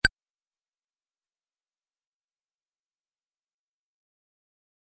audio-bleep-04.mp3